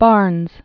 (bärnz), Albert Coombs 1872-1951.